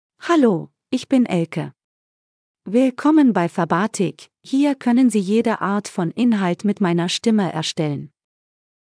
Elke — Female German (Germany) AI Voice | TTS, Voice Cloning & Video | Verbatik AI
FemaleGerman (Germany)
Voice sample
Female
Elke delivers clear pronunciation with authentic Germany German intonation, making your content sound professionally produced.